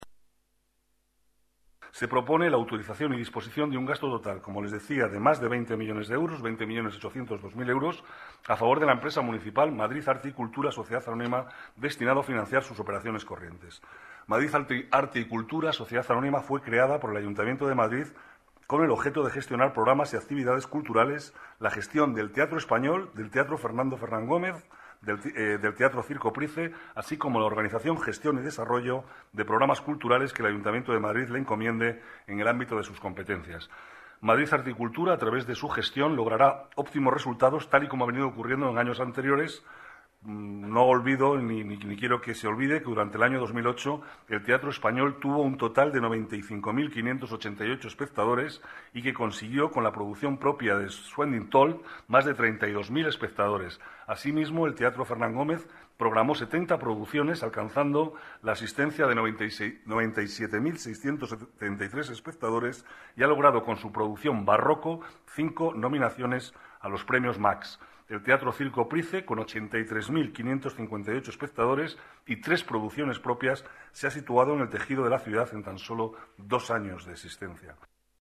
Nueva ventana:Declaraciones del vicealcalde Manuel Cobo: Madrid Arte y Cultura